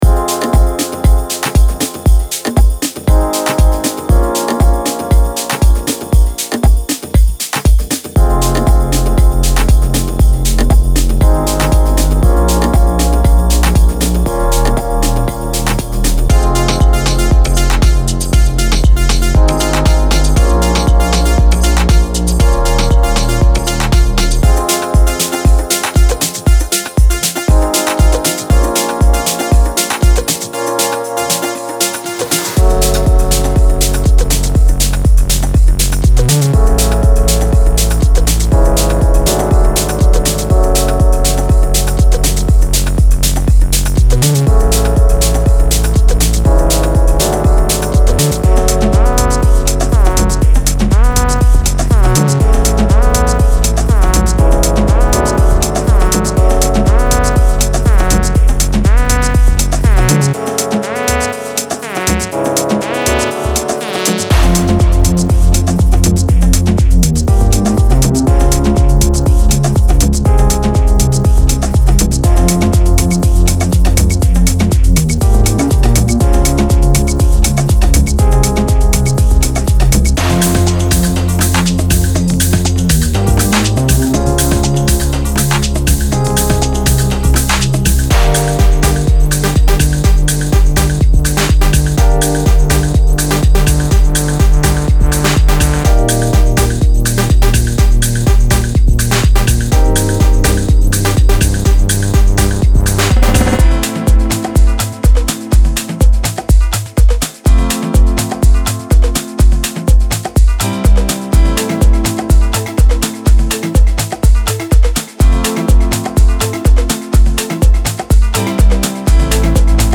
デモサウンドはコチラ↓